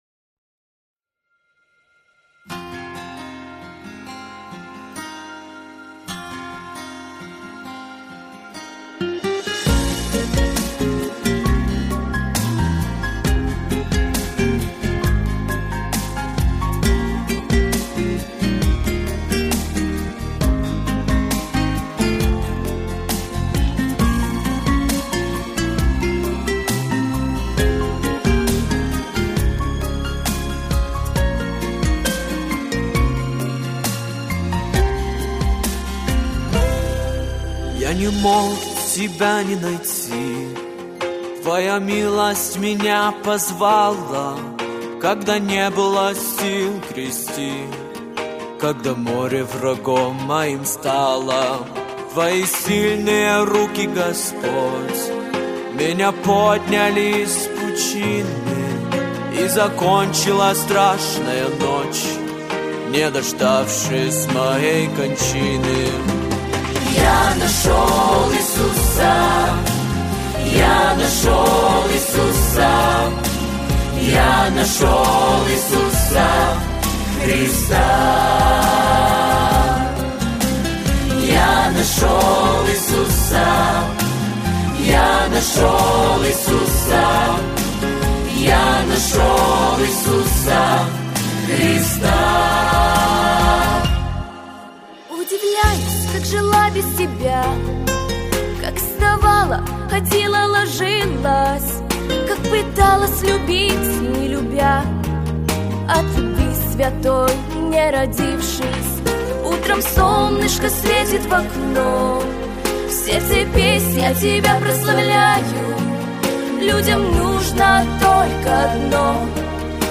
• Жанр: Детские песни
христианские песни